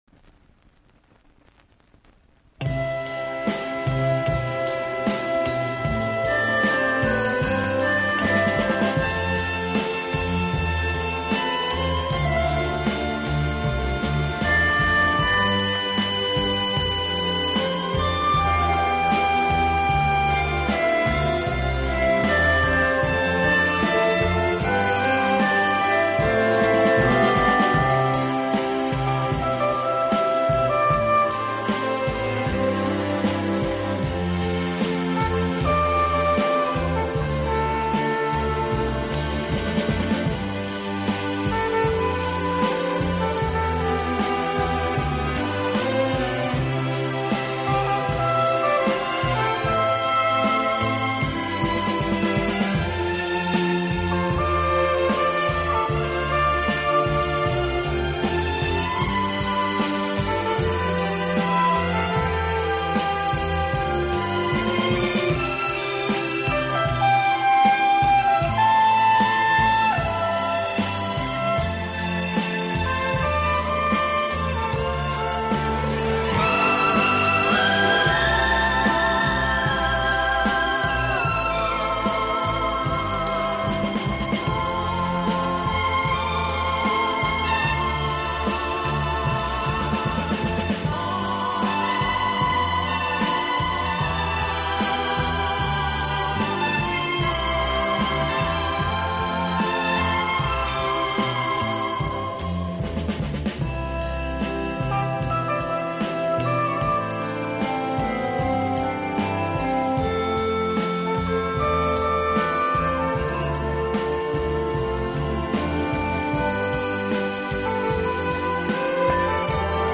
体，曲风浪漫、优雅，令人聆听時如感轻风拂面，丝丝柔情触动心扉，充分领略